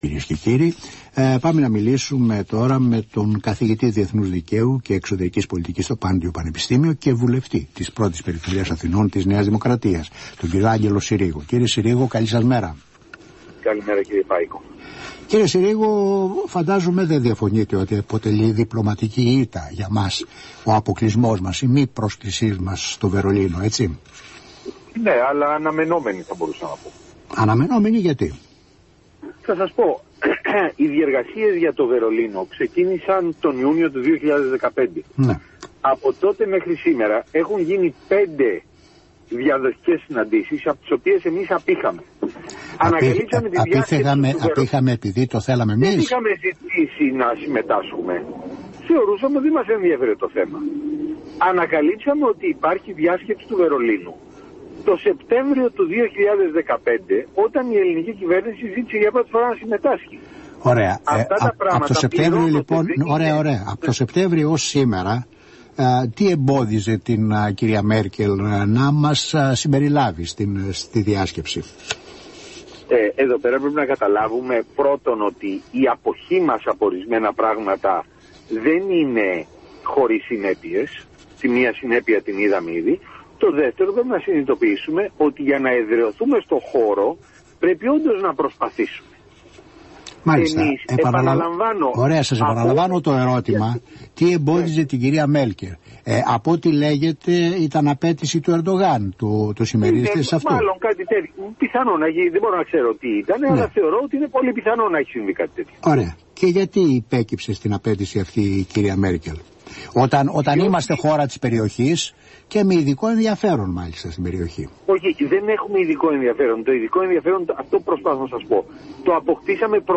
Για αναμενόμενη διπλωματική ήττα όσον αφορά τη μη πρόσκληση της Ελλάδας στην Διάσκεψη του Βερολίνου, έκανε λόγο ο βουλευτής της ΝΔ και αναπληρωτής καθηγητής Διεθνούς Δικαίου και Εξωτερικής Πολιτικής στο Πάντειο Πανεπιστήμιο, Άγγελος Συρίγος, στον Αθήνα 984.